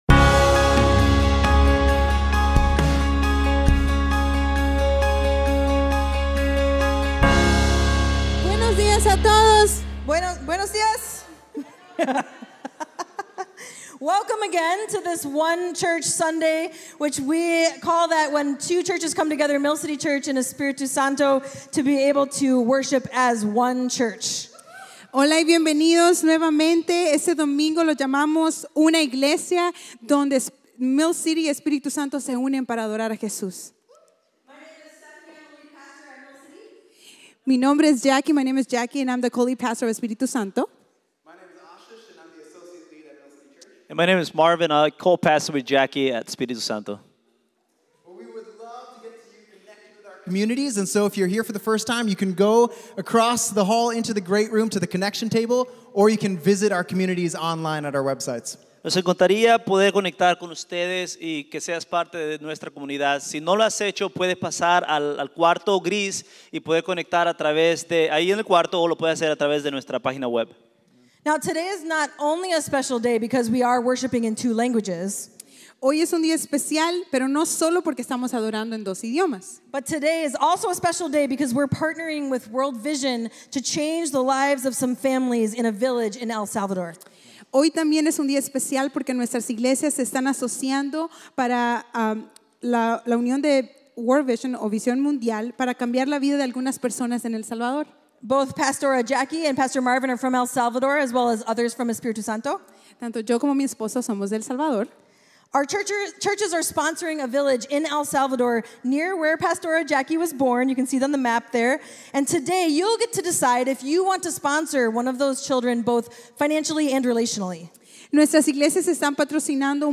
Mill City Church Sermons One Church Sunday: Chosen Sep 10 2024 | 00:40:51 1x 00:00 / 00:40:51 Subscribe Share RSS Feed Share Link Embed